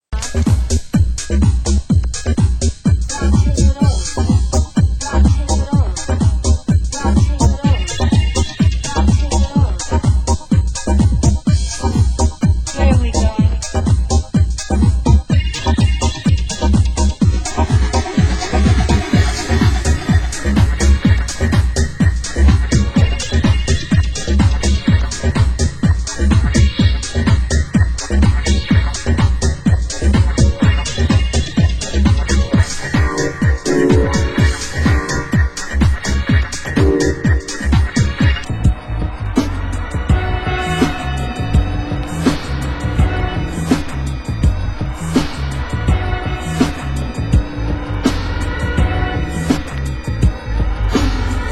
Genre: Tech House
Genre: Deep House